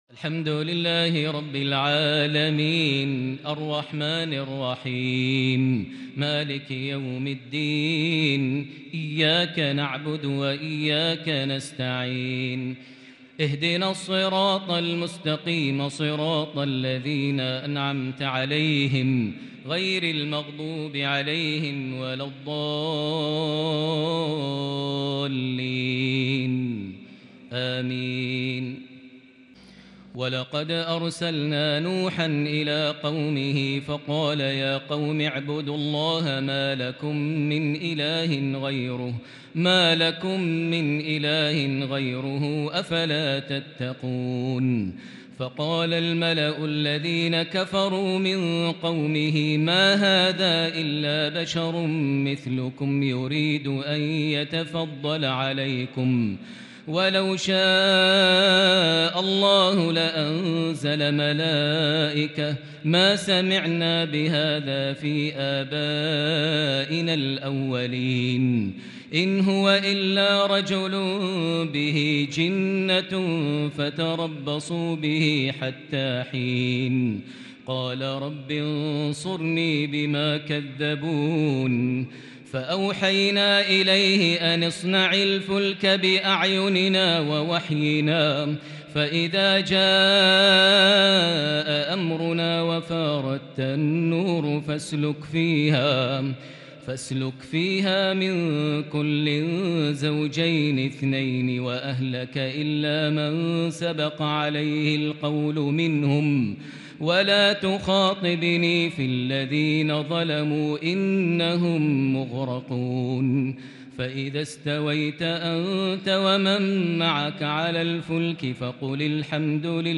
تراويح ليلة 22 رمضان 1442هـ سورة المؤمنون (23) | taraweeh 22st niqht Surah Al-Mumenoon (23) 1442H > تراويح الحرم المكي عام 1442 🕋 > التراويح - تلاوات الحرمين